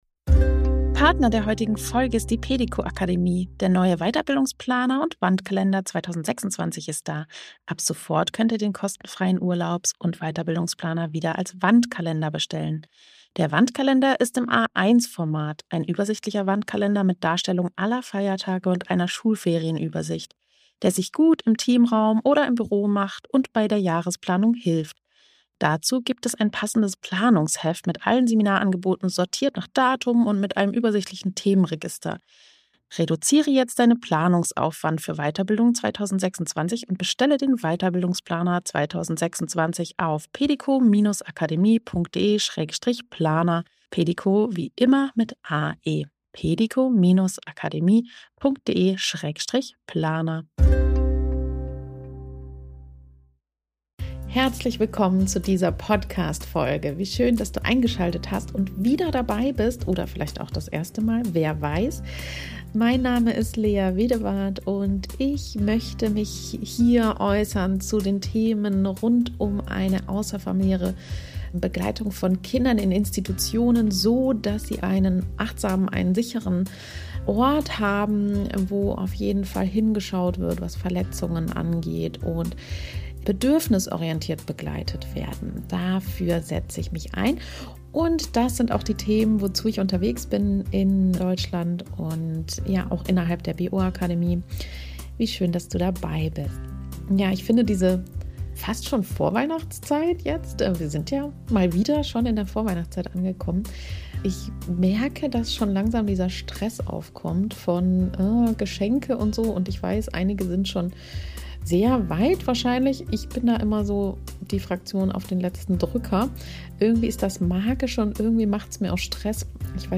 Ich teile mit dir einen Live-Mitschnitt meines Vortrags beim LAG Freie Kita-Träger Hessen e.V. zu meinem Buch „Ankommen dürfen s...